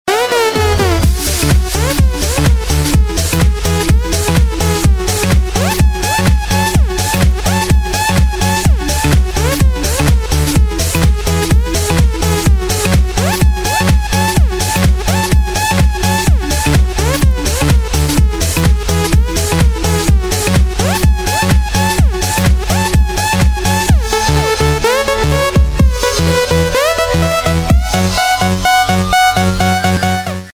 КЛУБНЫЕ РИНГТОНЫ
Категория: Из клубной музыки